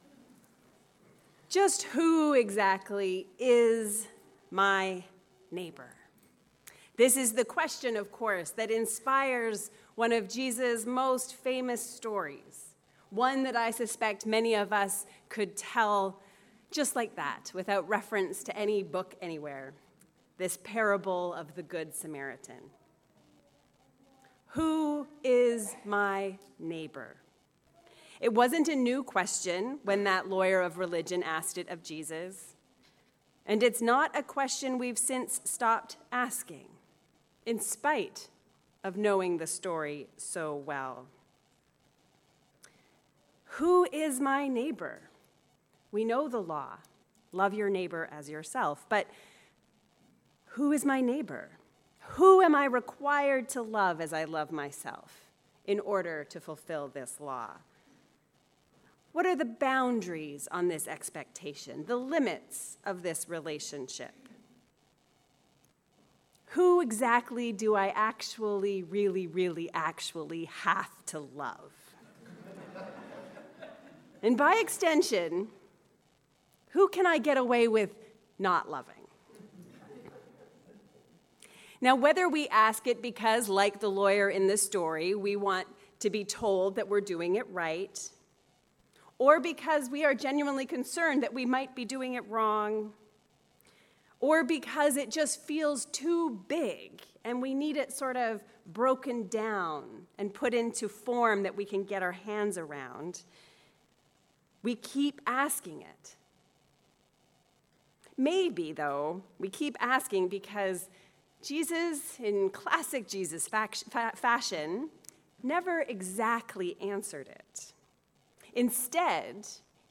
Being a neighbour. A sermon on the Good Samaritan and Baptism